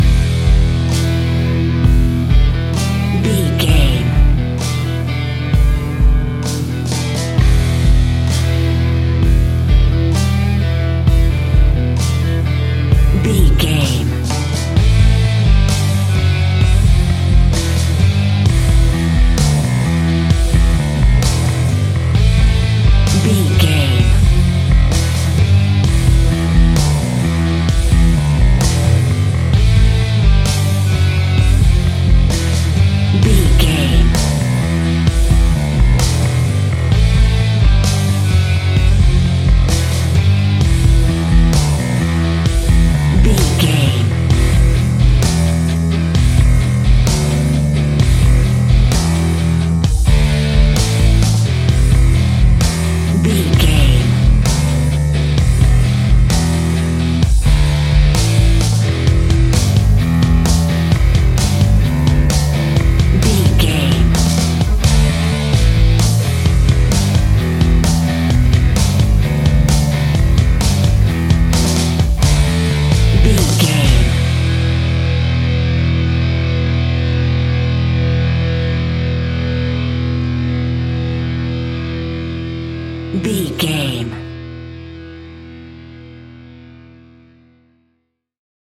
Epic / Action
Fast paced
Aeolian/Minor
hard rock
blues rock
distortion
instrumentals
rock guitars
Rock Bass
Rock Drums
heavy drums
distorted guitars
hammond organ